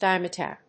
/ˈdaɪmʌˌtæp(米国英語)/